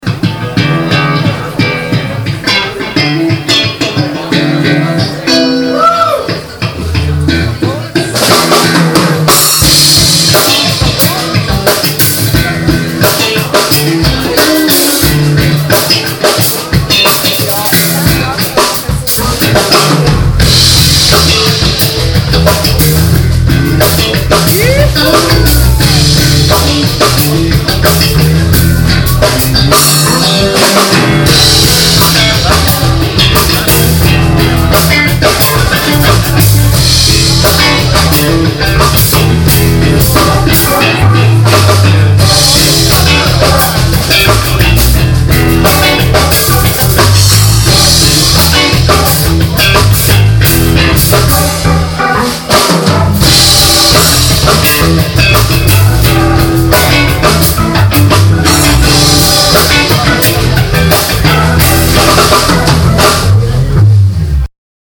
improvised solo bass slapping piece on guitar during a live show in De Platse
slap bass rolleg
Slap_Guitar_Groove_live.mp3